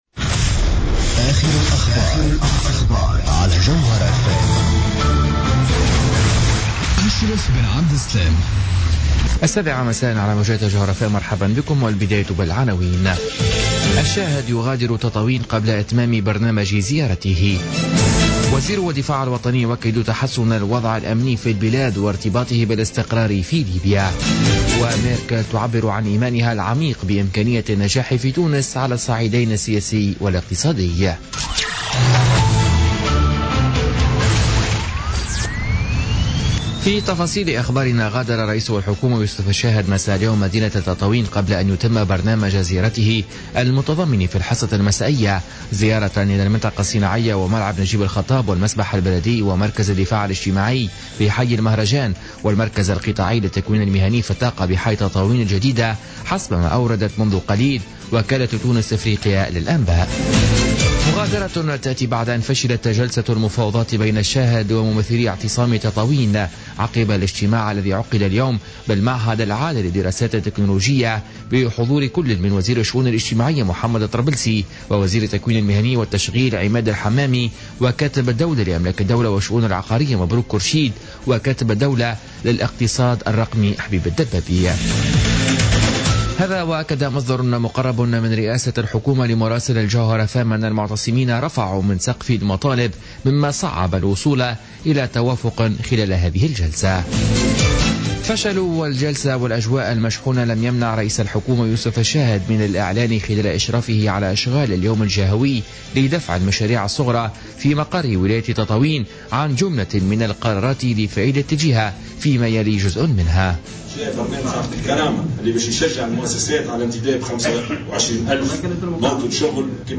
نشرة أخبار السابعة مساء ليوم الخميس 27 أفريل 2017